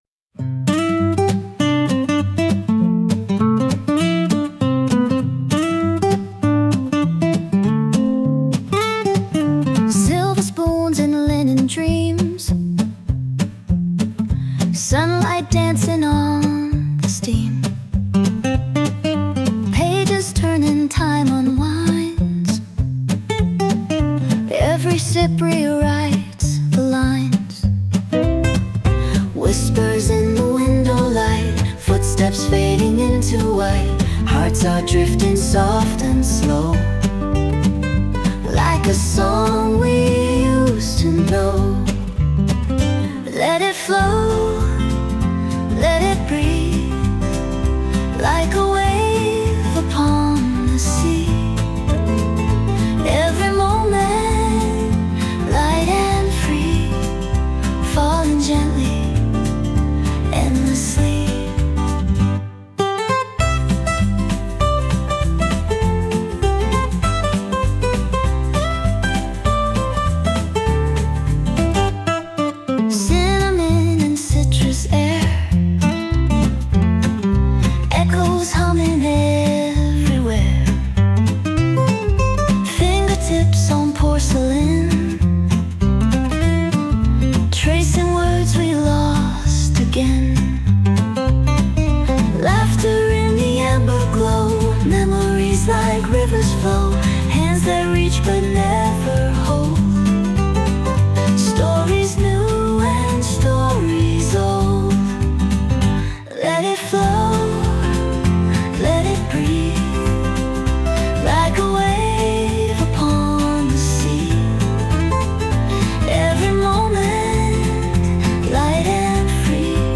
洋楽女性ボーカル著作権フリーBGM ボーカル
女性ボーカル（洋楽・英語）曲です。